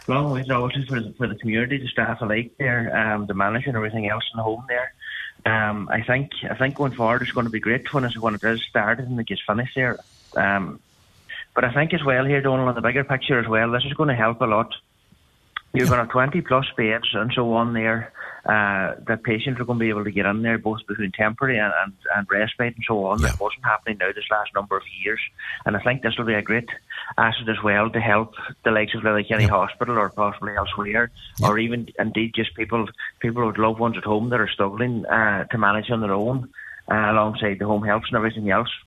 Councillor Michael McClafferty says once fully operational, the hospital will provide relief for other areas such as Letterkenny University Hospital which is under significant pressure: